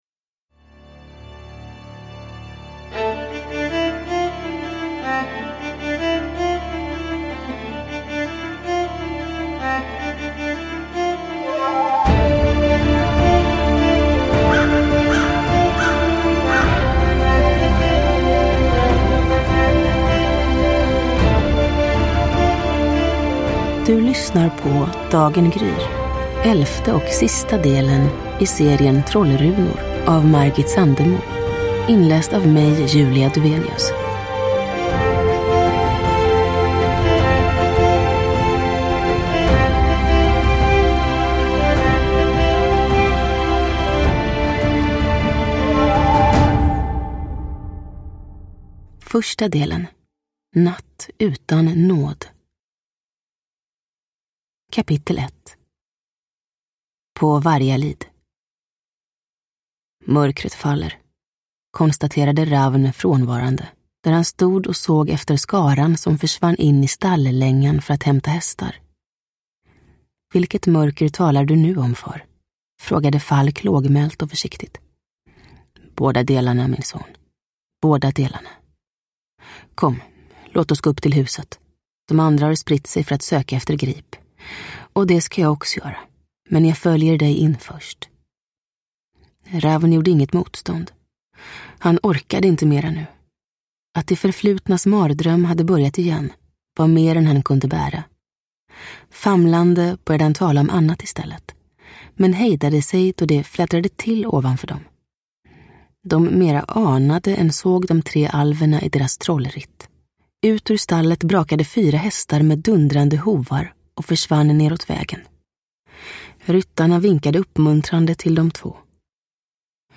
Dagen gryr – Ljudbok – Laddas ner
Uppläsare: Julia Dufvenius